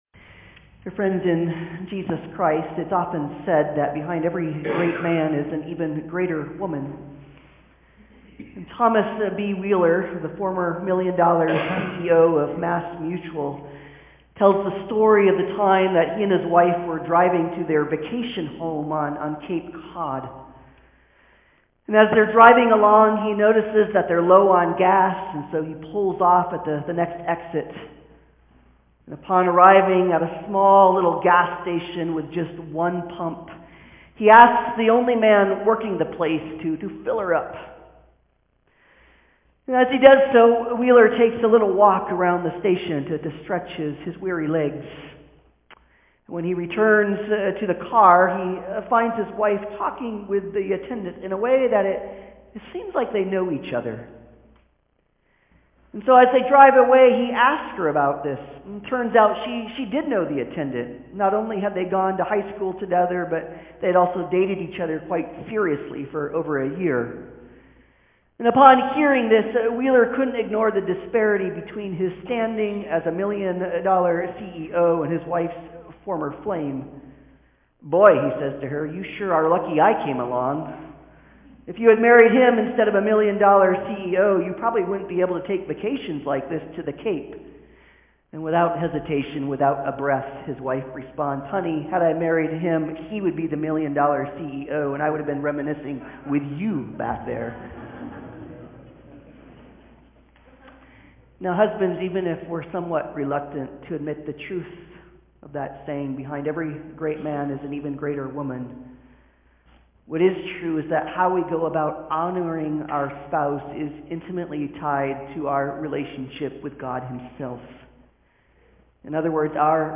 Proverbs 31:10-31 Service Type: Sunday Service « The Parable of the Net